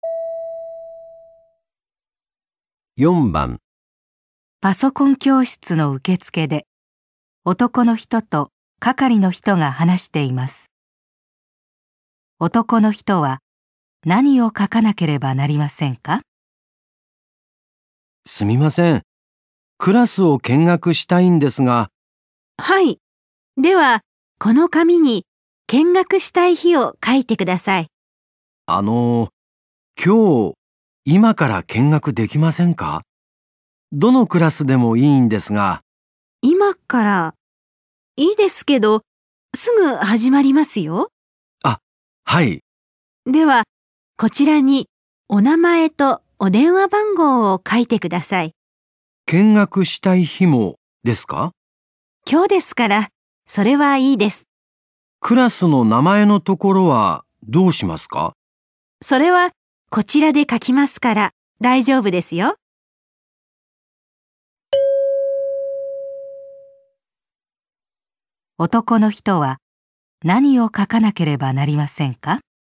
在电脑培训班的前台，男人和工作人员在说话。